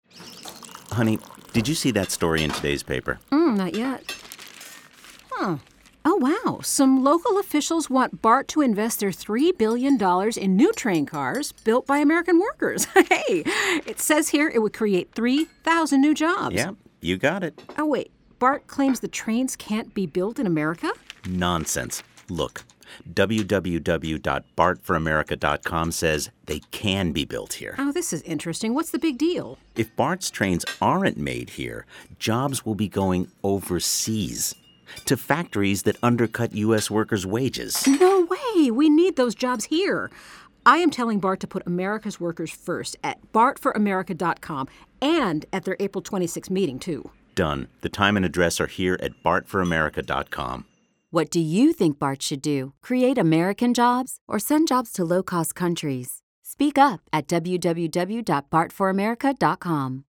Nun schreit das patriotische Amerika laut auf (und schaltet solche Radio-Spots):